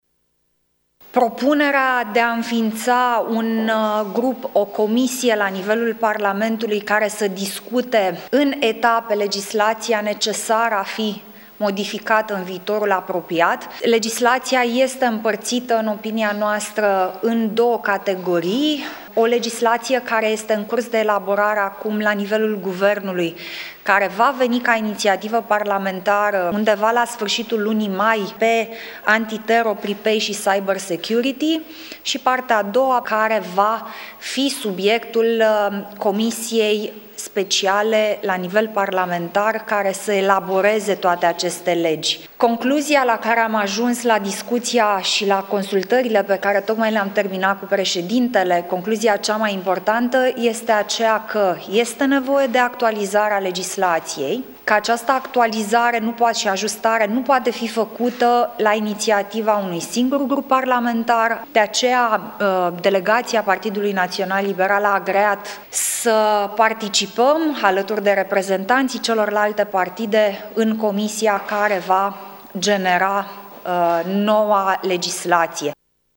Copreședintele PNL, Alina Gorghiu: